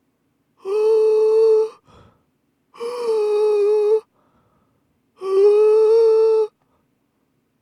吸気の裏声が上手く鳴らせないパターン2
音にならない／全く伸ばせない
音量注意！
こっちはパターン１とは逆に声帯を開きすぎて全く触れ合っていない、もしくはギリギリ合わさってはいるけれど開く動きが強すぎてほとんどの息が音にならずに声帯を通過してしまっているという状態です。